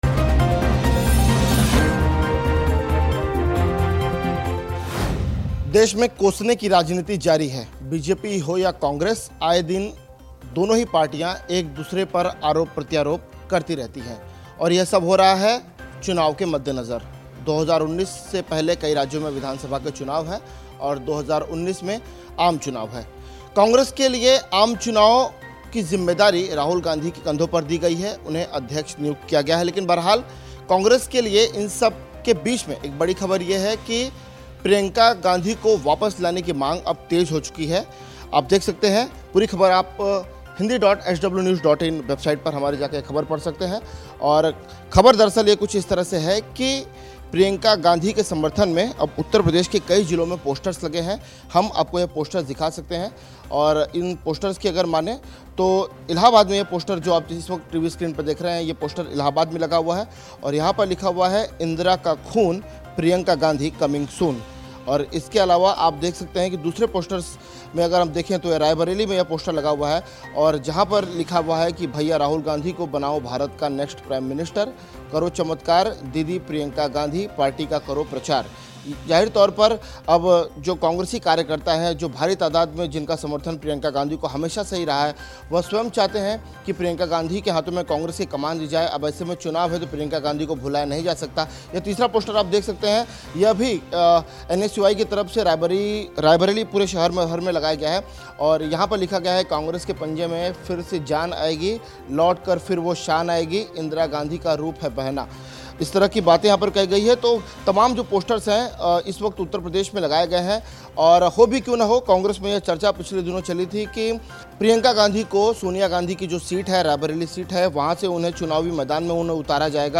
न्यूज़ रिपोर्ट - News Report Hindi / कांग्रेस की बढ़ी लोकप्रियता, प्रियंका गांधी की चुनाव में एंट्री से बीजेपी टेशन में !